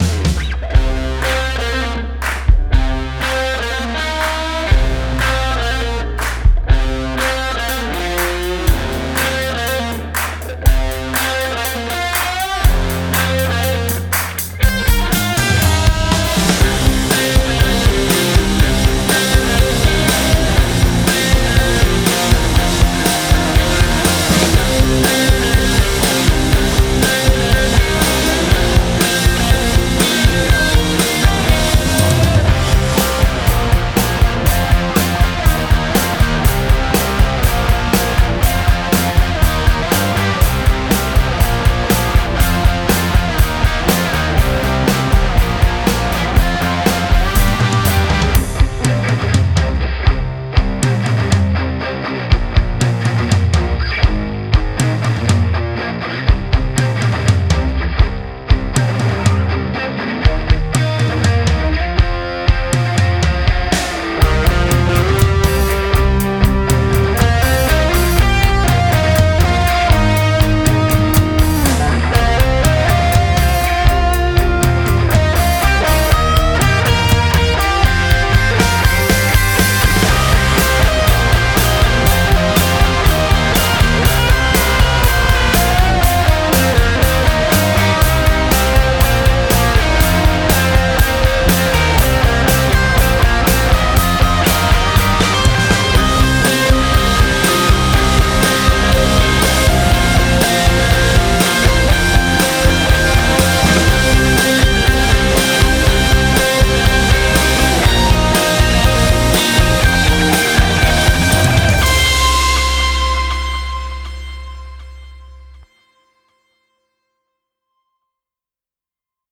Uplifting, Action - Commercial / Rock